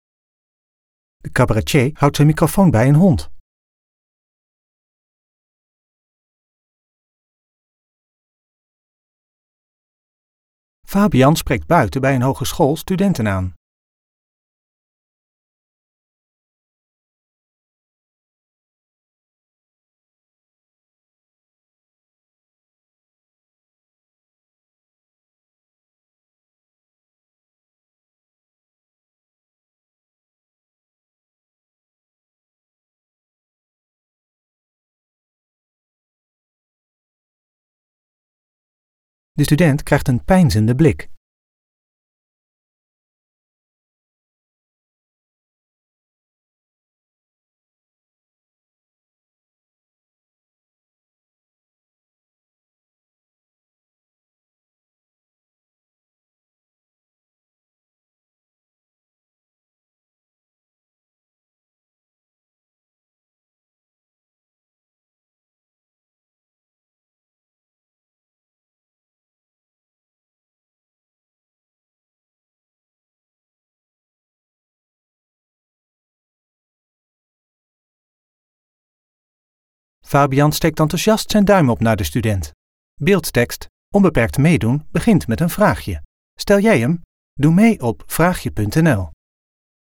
In deze videoreeks gaat hij de straat op om voorbijgangers vragen te stellen over toegankelijkheid.